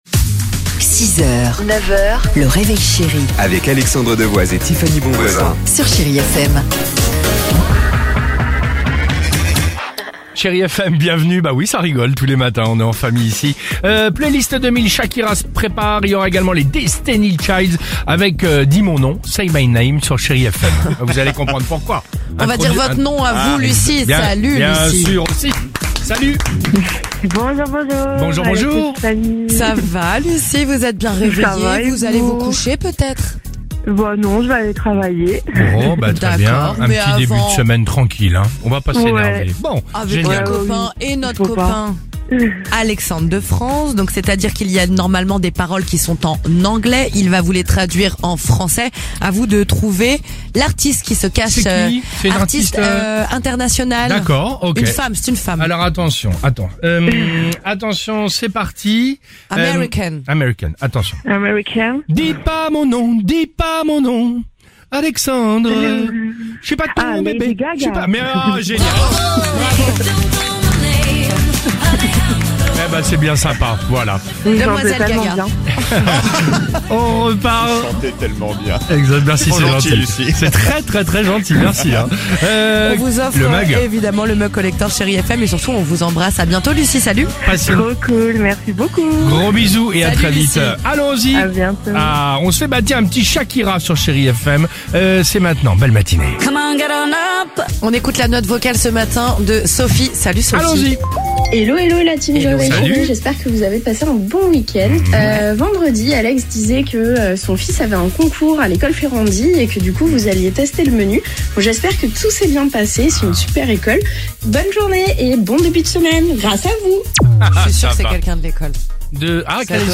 Réécoutez les meilleurs moments de l’émission ici avec le best-of du Réveil Chérie.